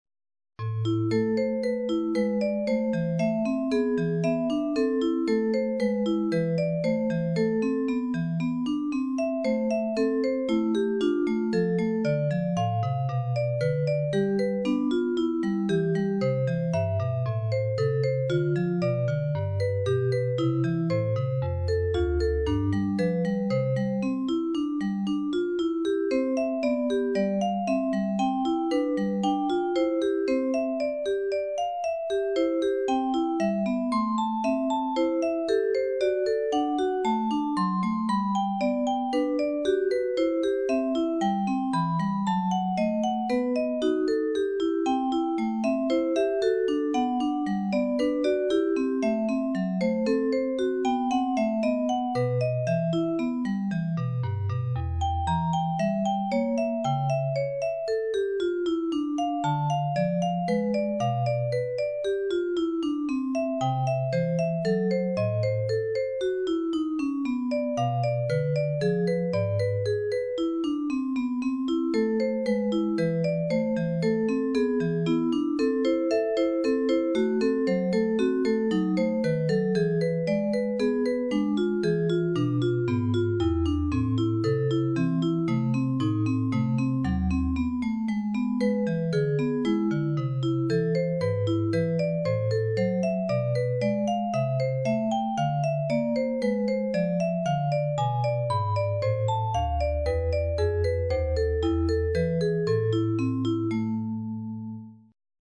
Esempi di musica realizzata con il sistema temperato